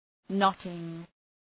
Προφορά
{‘nɒtıŋ}